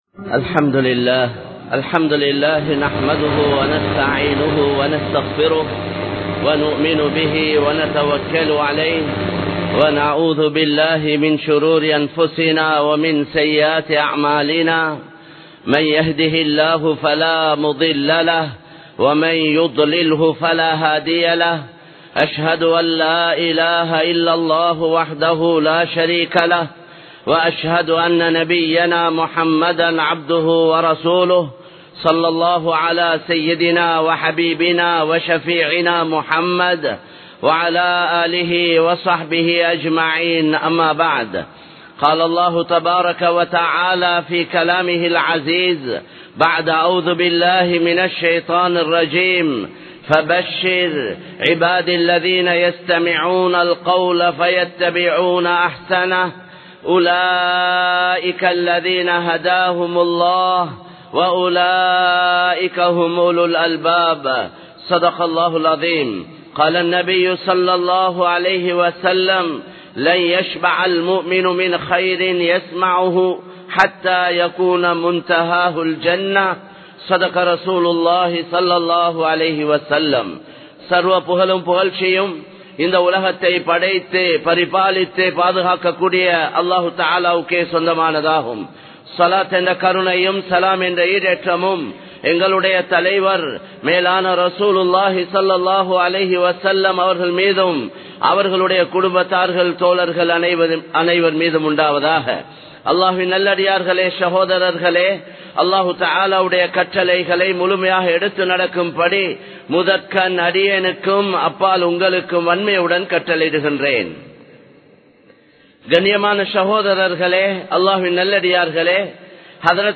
அல்லாஹ்வை மறந்து பேசாதீர்கள் | Audio Bayans | All Ceylon Muslim Youth Community | Addalaichenai
Kollupitty Jumua Masjith